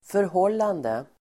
Uttal: [förh'ål:ande]